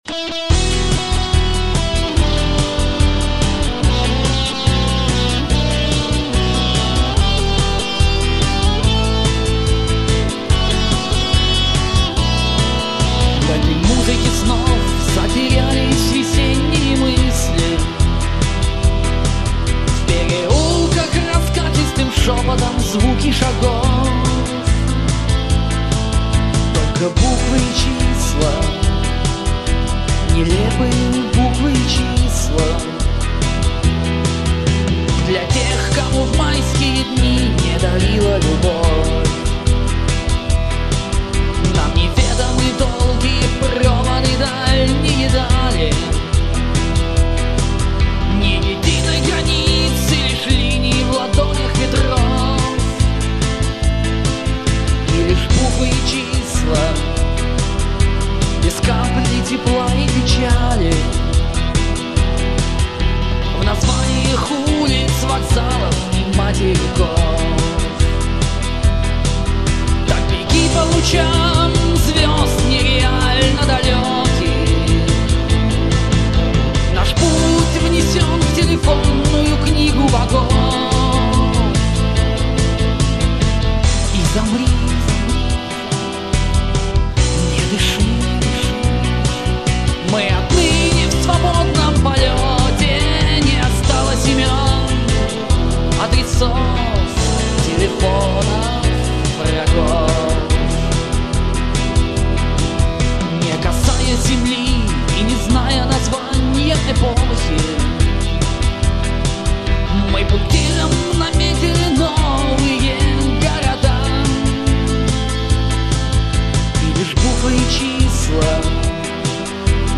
• Жанр: Арт-рок